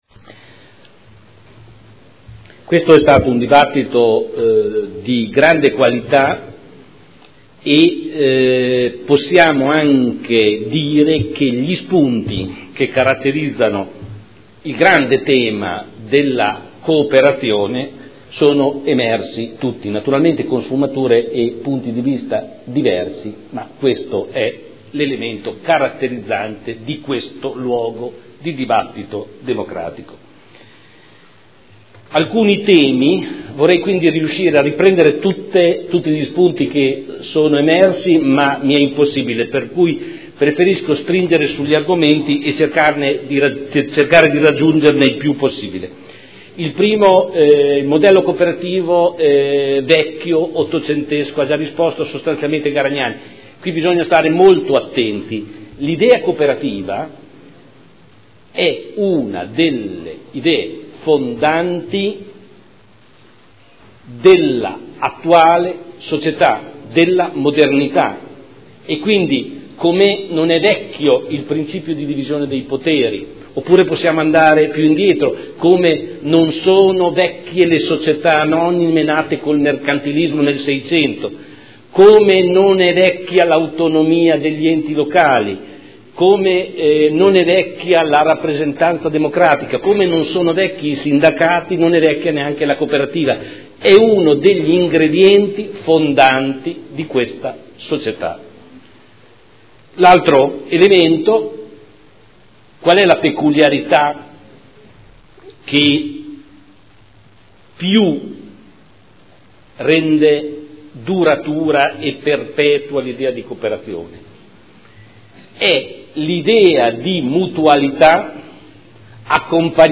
Giorgio Pighi — Sito Audio Consiglio Comunale
Seduta del 22/11/2012. Dibattito su celebrazione dell’Anno internazionale delle cooperative indetto dall’ONU per il 2012